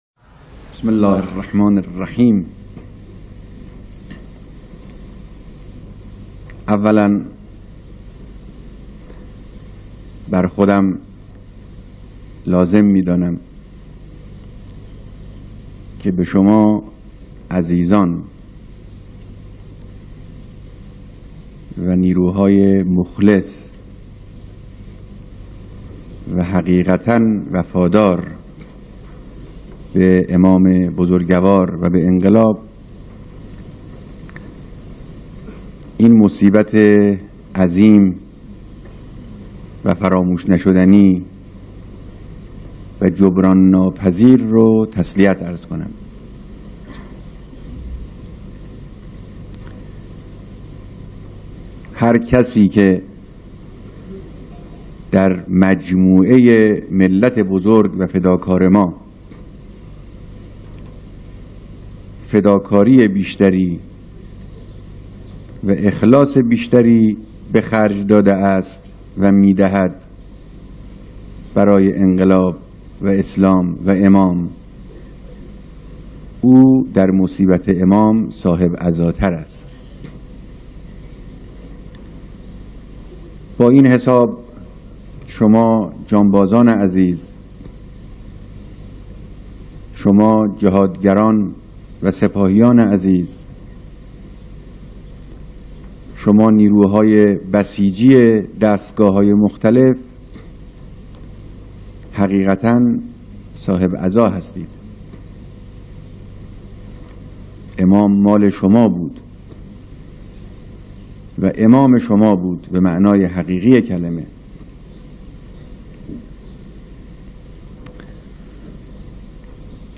بیانات رهبر انقلاب در مراسم بیعت مسئولان احداث مرقد امام خمینی(ره)